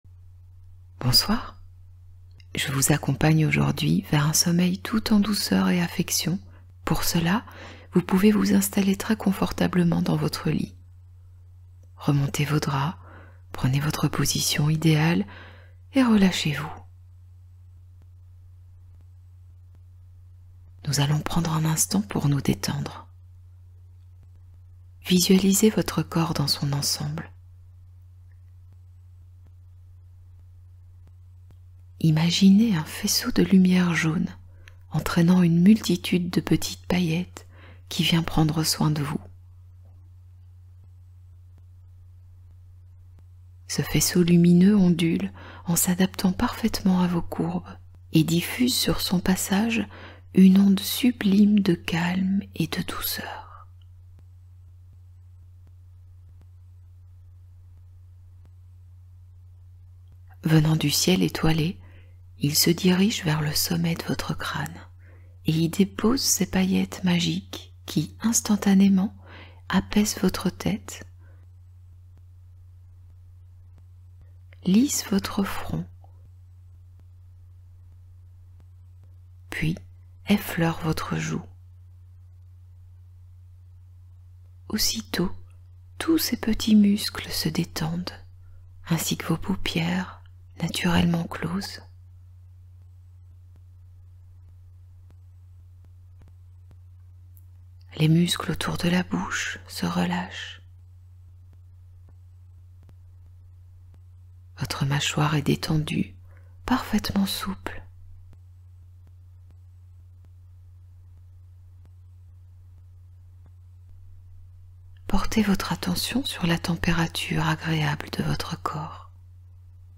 Hypnose du bonheur : tendresse et douceur pour s’endormir